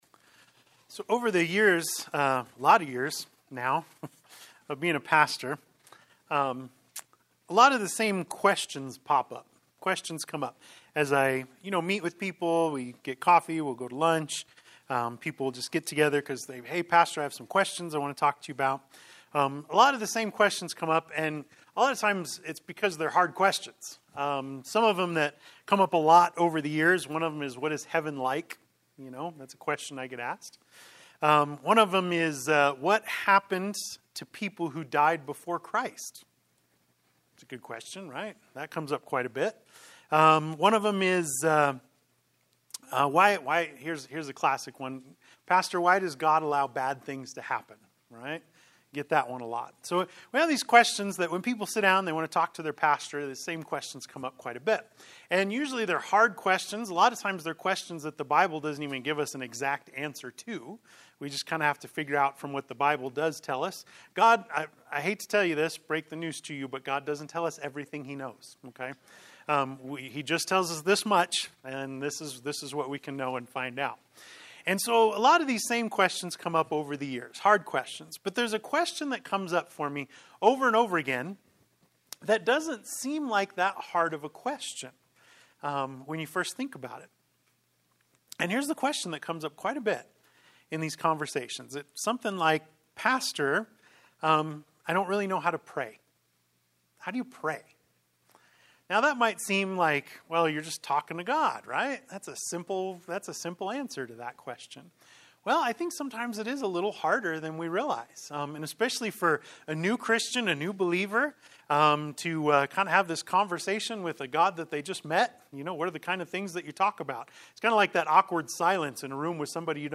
Sermon-4-23-23.mp3